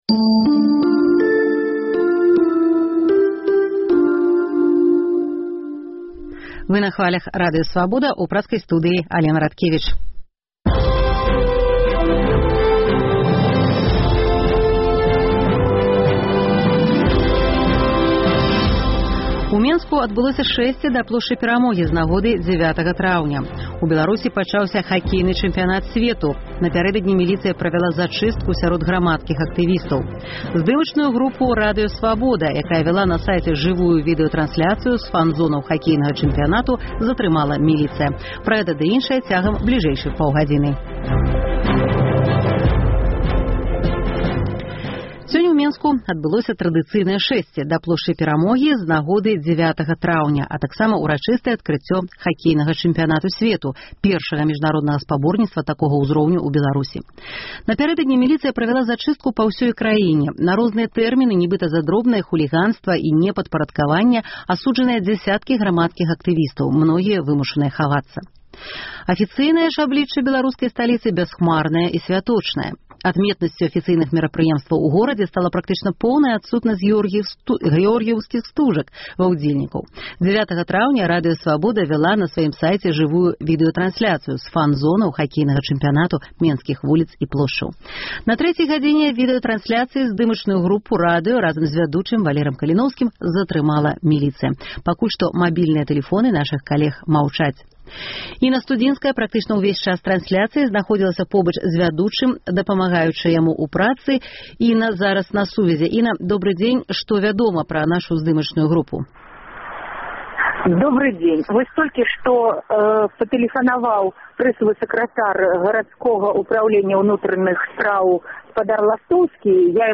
Пра тых, хто сьвяткуе і тых, каму не да сьвята – у жывым эфіры Свабоды.